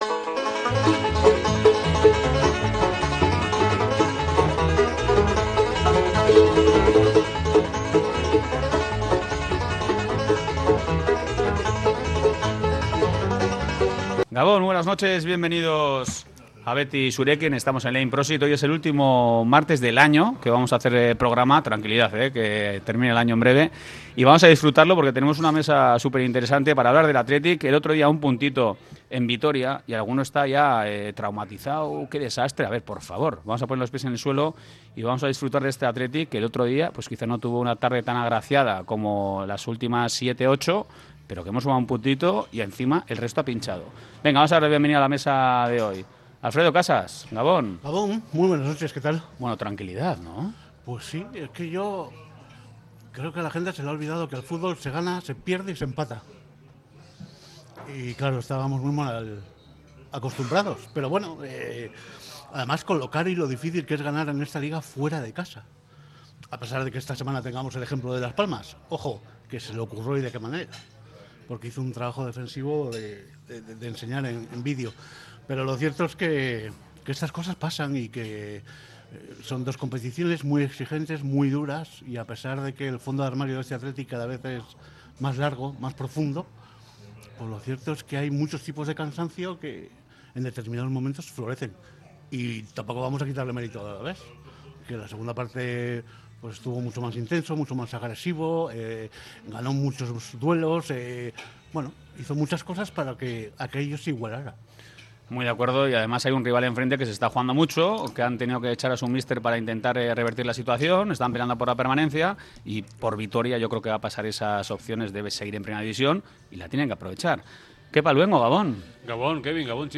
mesa redonda